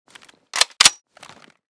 mp7_unjam.ogg.bak